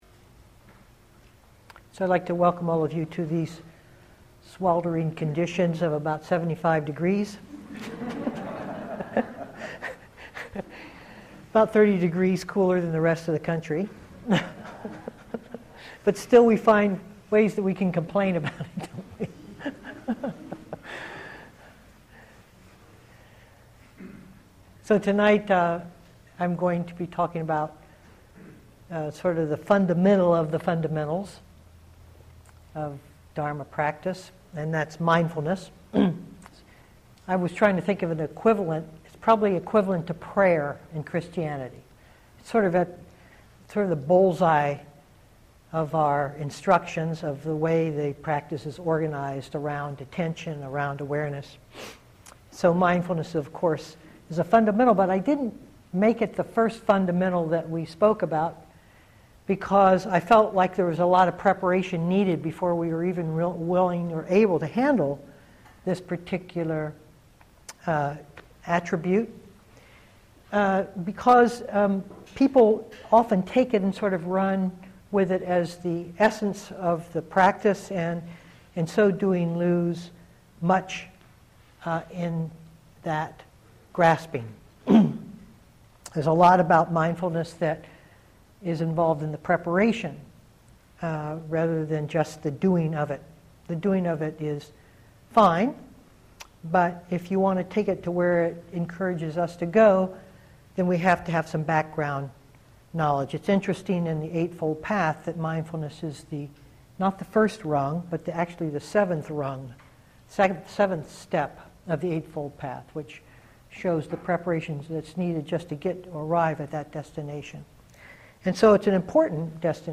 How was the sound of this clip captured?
2012-07-10 Venue: Seattle Insight Meditation Center